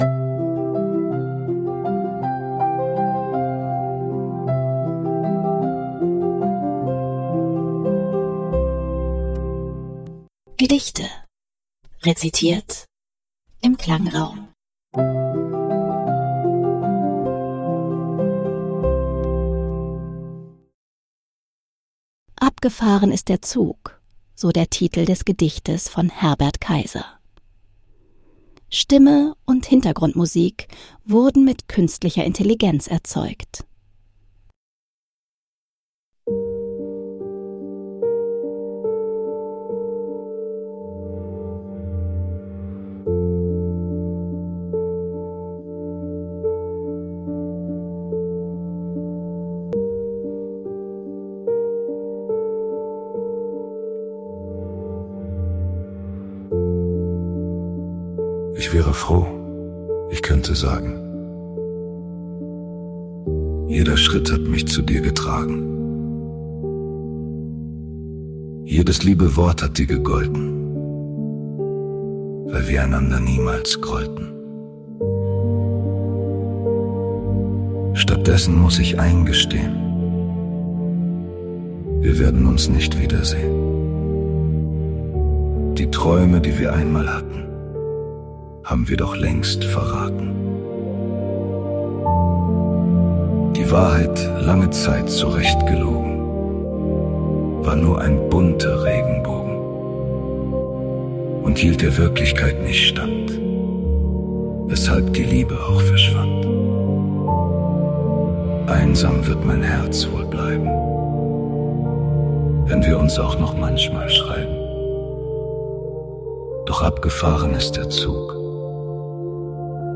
mithilfe Künstlicher Intelligenz erzeugt.